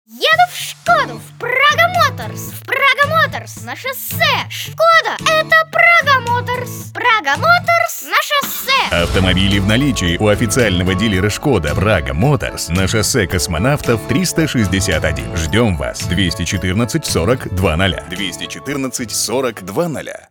Хорошо играет интонациями.
Тракт: МИКРОФОН SE ELECTRONICS Z 5600A II , аудио интерфейс STEINBERG UR22C